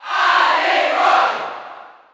Category: Crowd cheers (SSBU) You cannot overwrite this file.